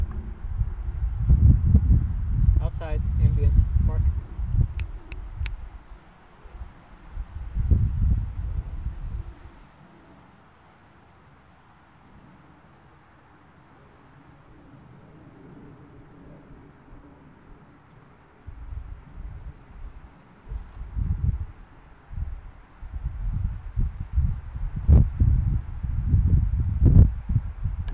亚太地区 " 越南骆驼旅游巴士
描述：在越南高速公路上的可怕可怕的公共汽车。奇怪的号角。上帝，我恨这辆巴士。
Tag: 交通运输 交通 公路 高速公路 公交车